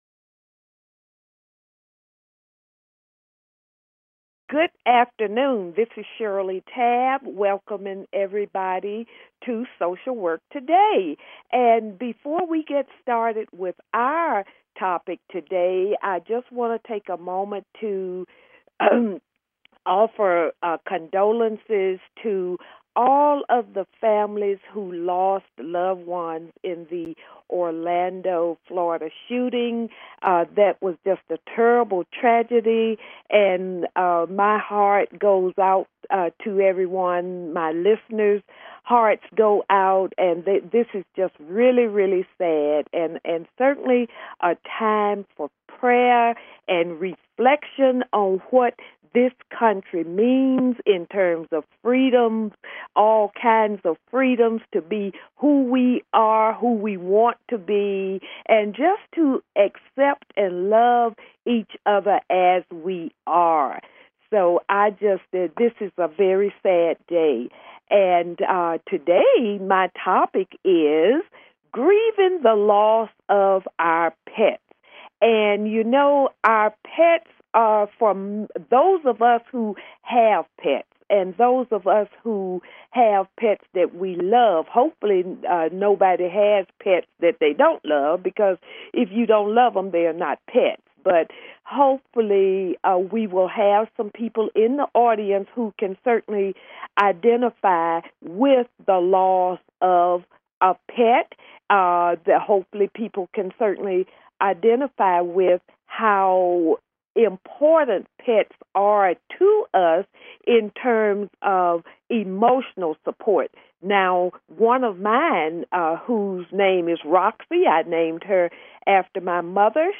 Call-ins are welcomed, even encouraged.